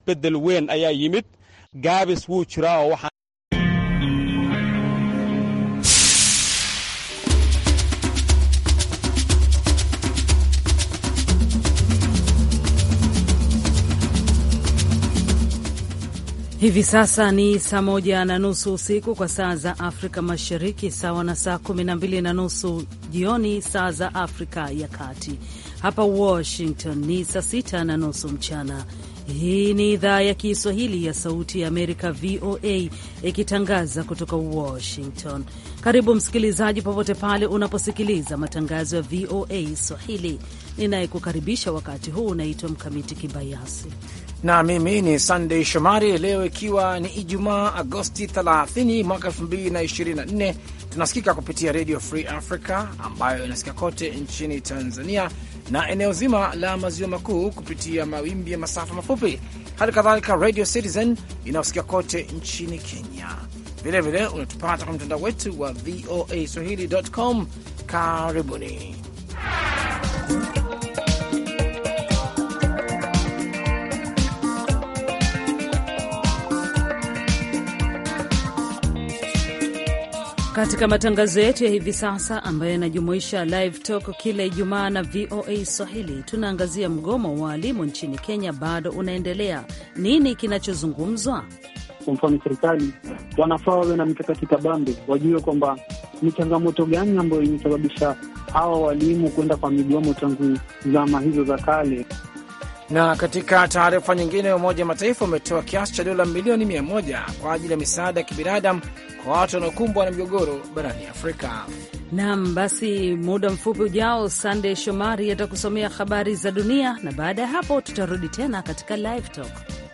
Matangazo ya saa nzima kuhusu habari za kutwa, ikiwa ni pamoja ripoti kutoka kwa waandishi wetu sehemu mbali mbali duniani na kote Afrika Mashariki na Kati, na vile vile vipindi na makala maalum kuhusu afya, wanawake, jamii na maendeleo.